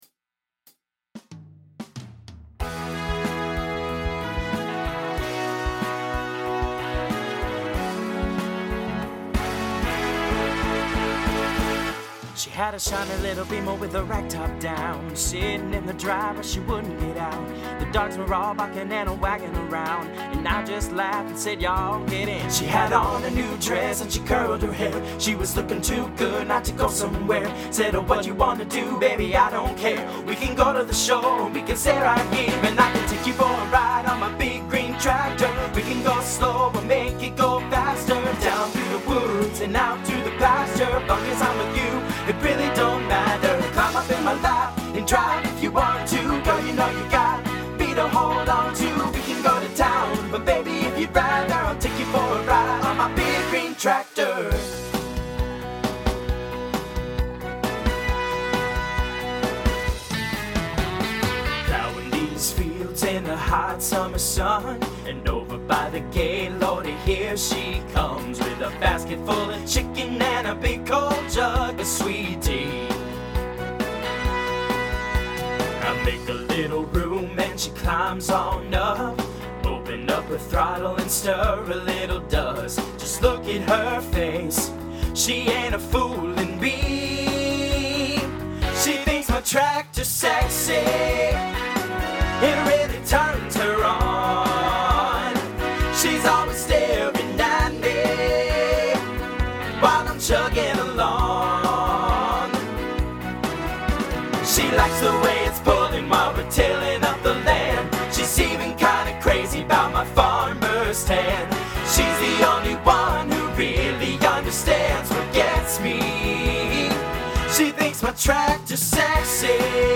Voicing TTB Instrumental combo Genre Country